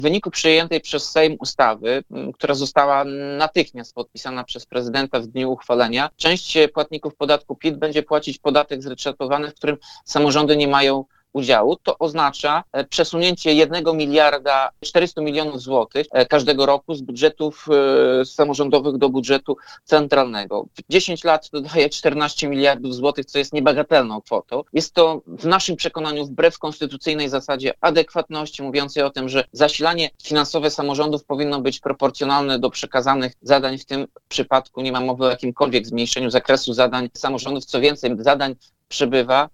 Mówi prezydent Ełku Tomasz Andrukiewicz.